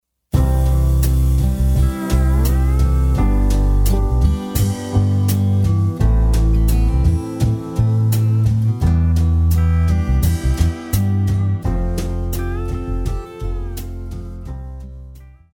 Classical
French Horn
Band
Etude,Course Material,Classical Music
Only backing